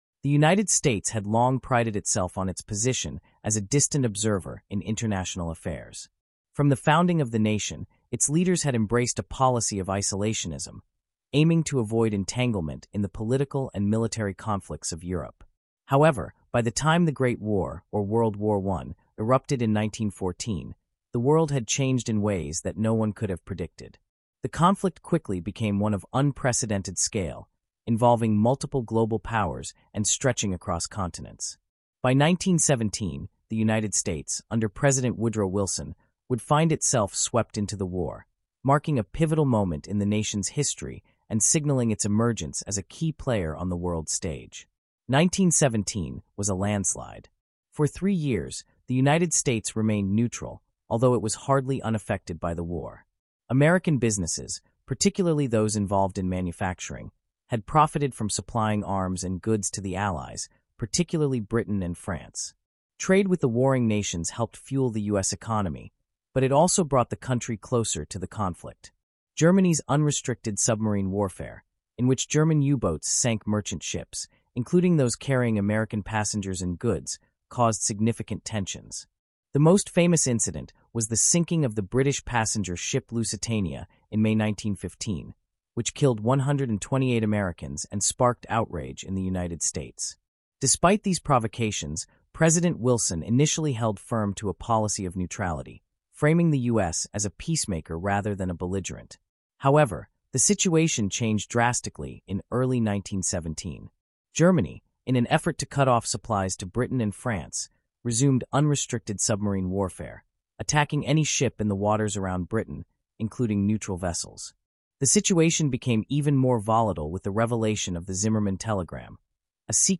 Disclosure: This podcast includes content generated using an AI voice model.